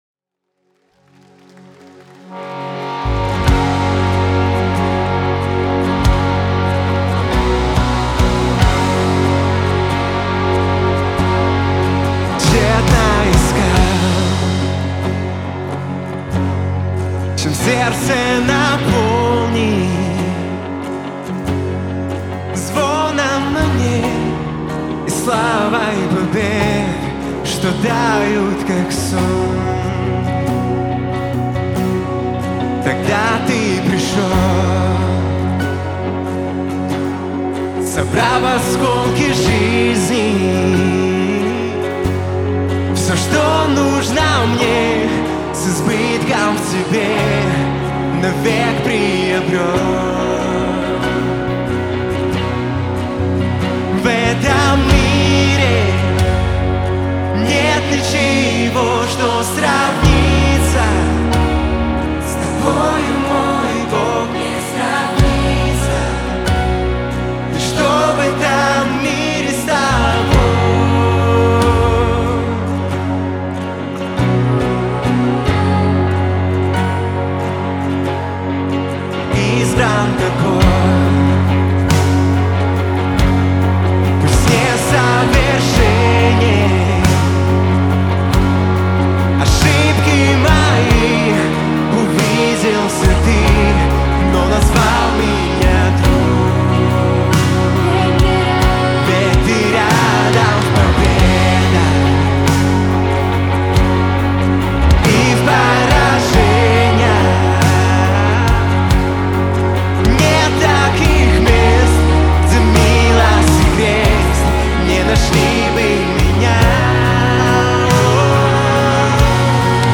198 просмотров 603 прослушивания 22 скачивания BPM: 70